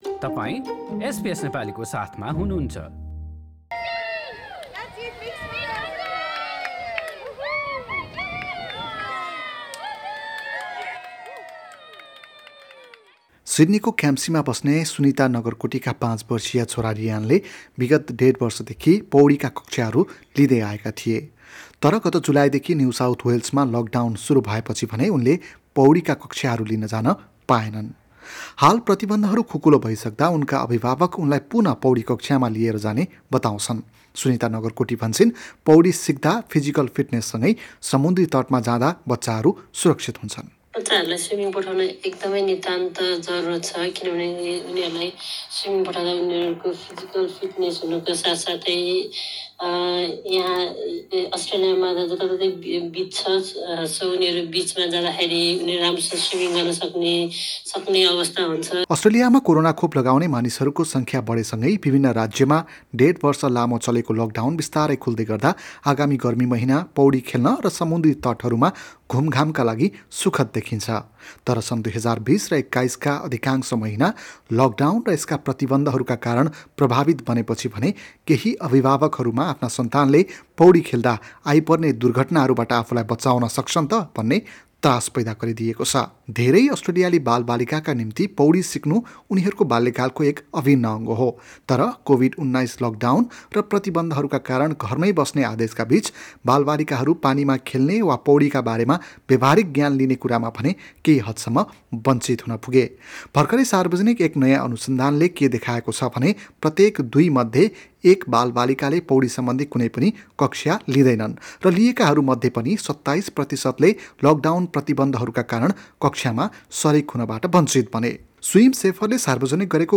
कुराकानी सहितको हाम्रो रिपोर्ट सुन्नुहोस्: null हाम्रा थप अडियो प्रस्तुतिहरू पोडकास्टका रूपमा उपलब्ध छन्।